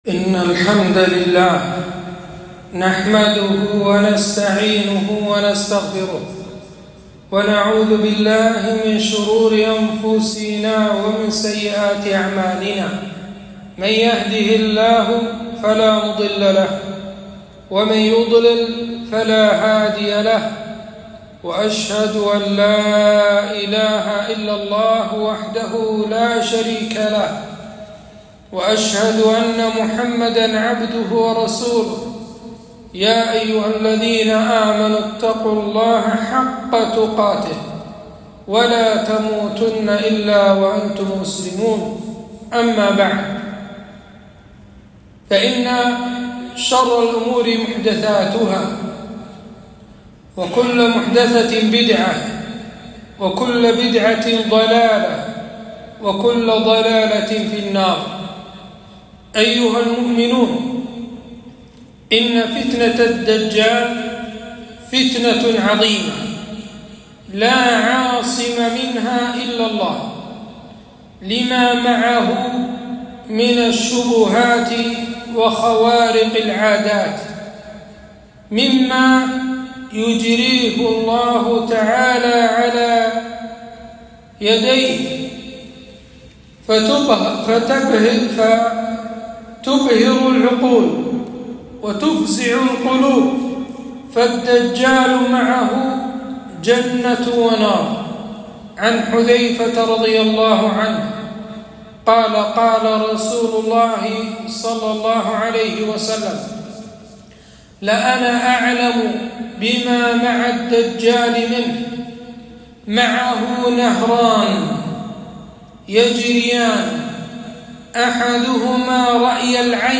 خطبة - الوقاية من فتنة المسيح الدجال - دروس الكويت